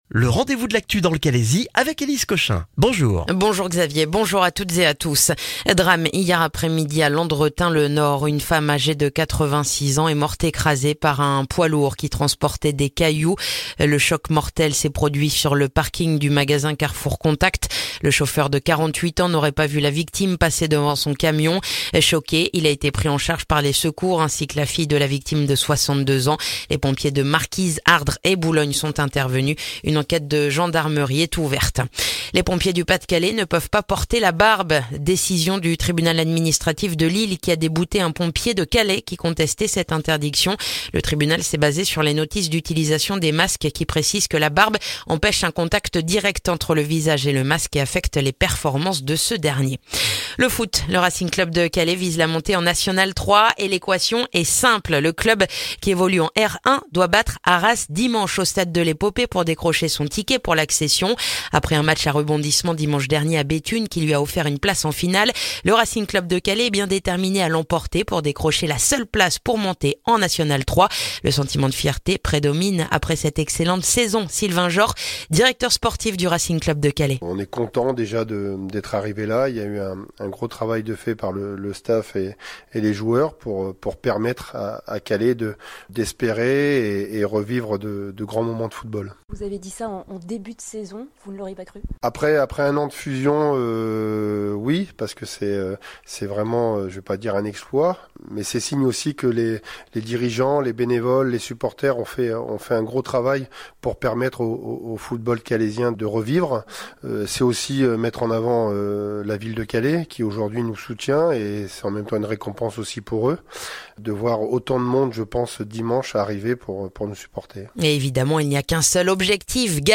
Le journal du jeudi 20 juin dans le calaisis